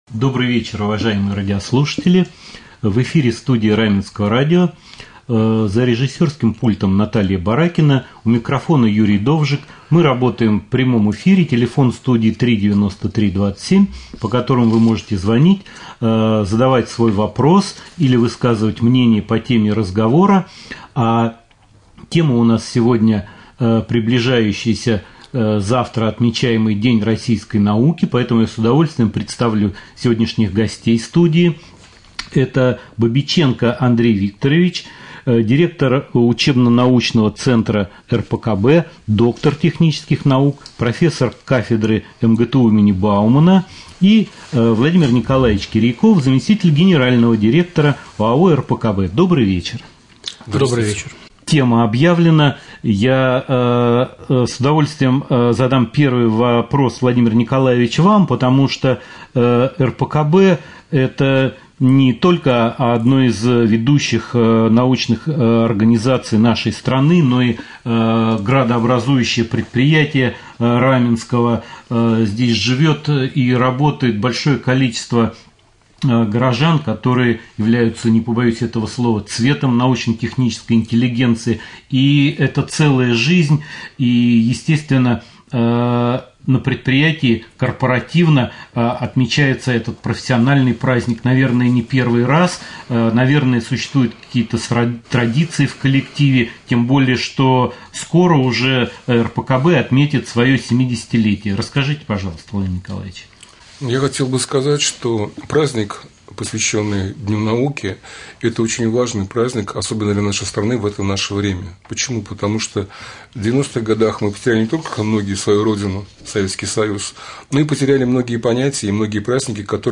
Ко Дню науки интервью Раменскому радио